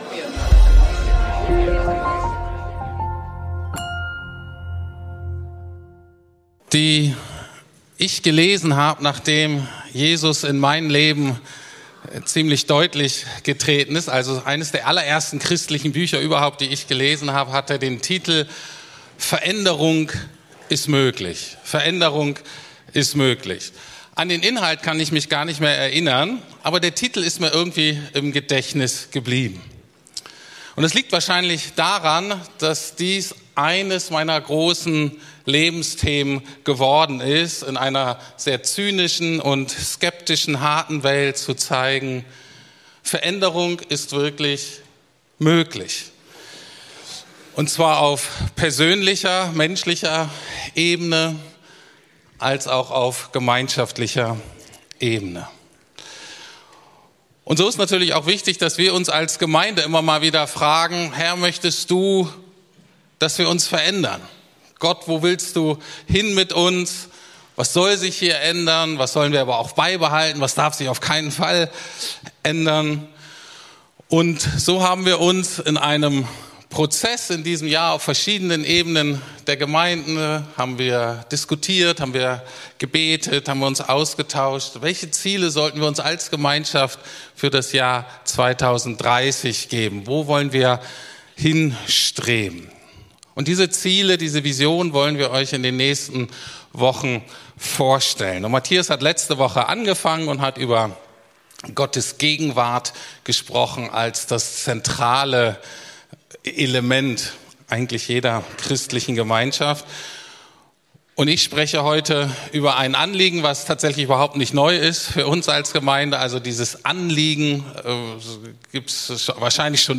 Wir wachsen im Glauben ~ Predigten der LUKAS GEMEINDE Podcast